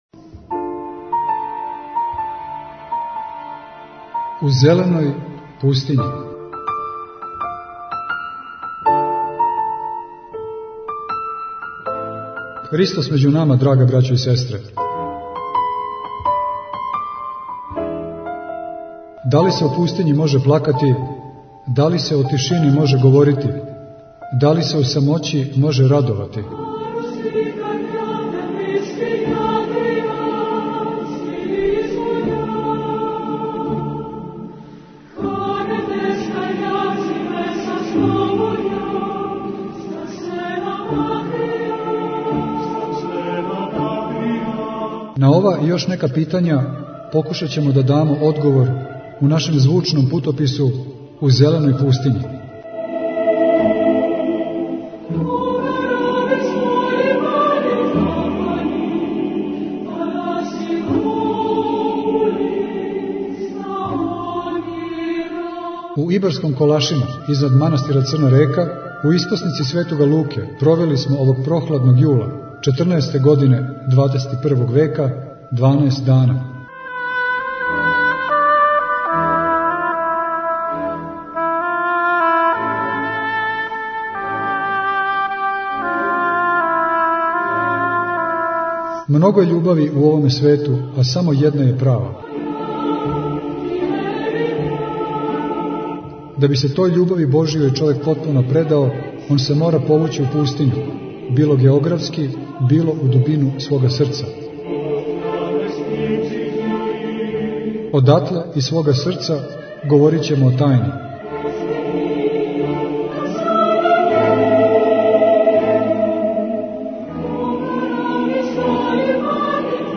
сабрат манастира Острог припремио је нову репортажу "У Зеленој пустињи".